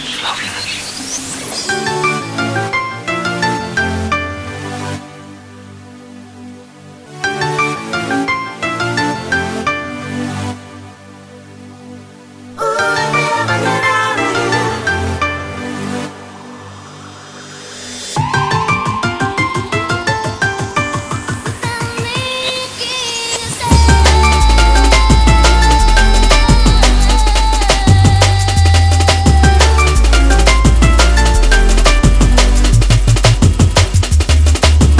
Dance Jungle Drum'n'bass
Жанр: Танцевальные